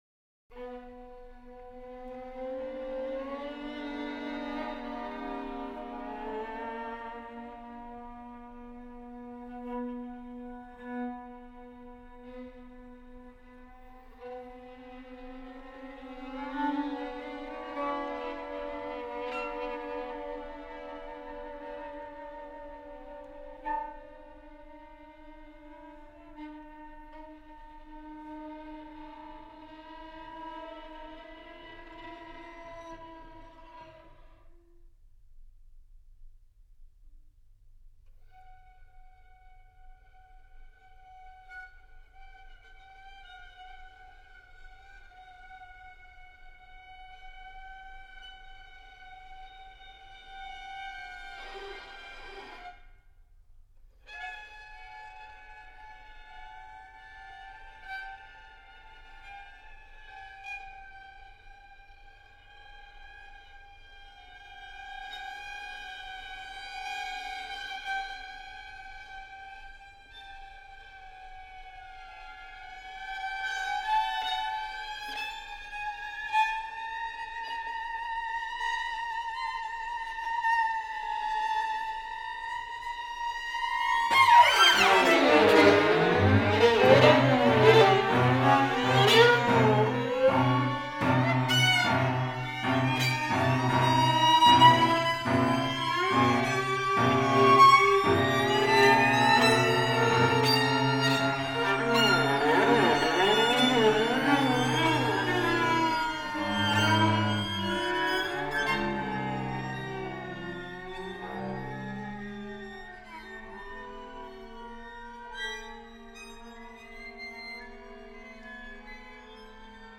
string quartet